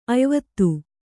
♪ ayvattu